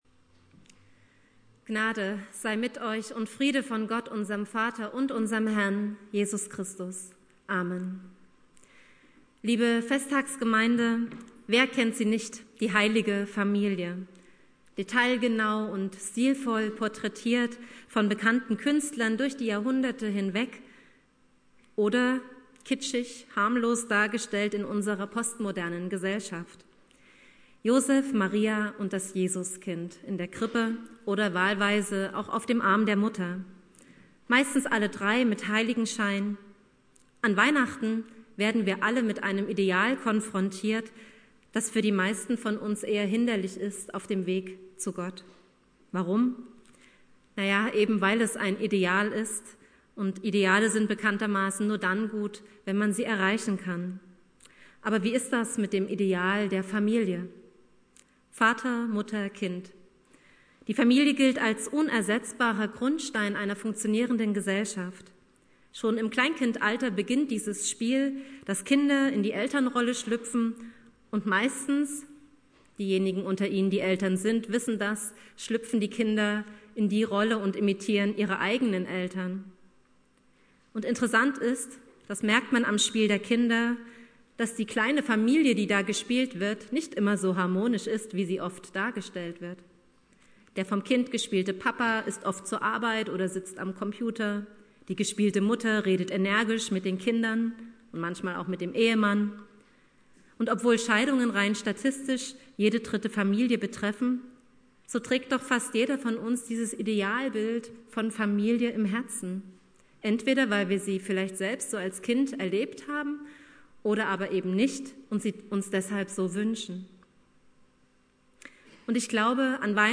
"Die Heilige Familie" (Predigt an Heiligabend 18 Uhr, Waldkirche) Dauer